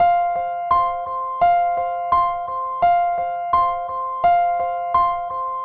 Index of /90_sSampleCDs/Zero-G - Total Drum Bass/Instruments - 2/track46 (Keyboards)
05 Arpegg 170 F.wav